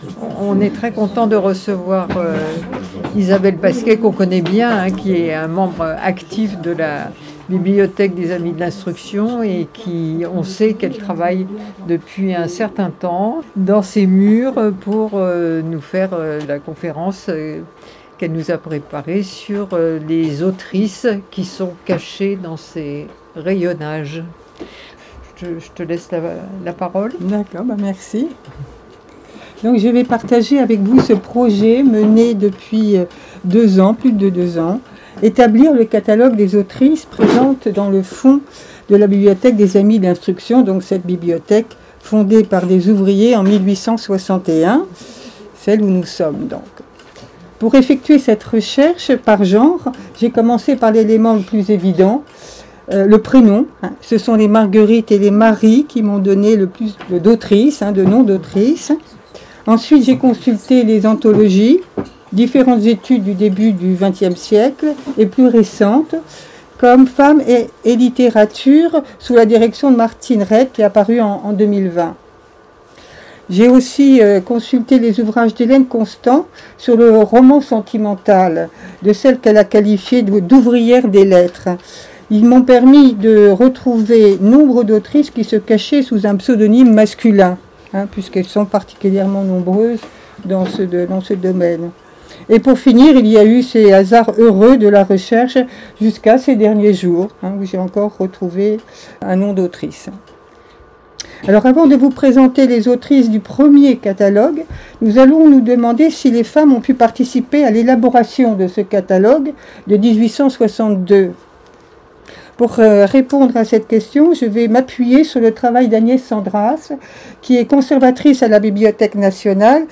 Conférence du 17 mai 2025 : Lire les autrices à la BAI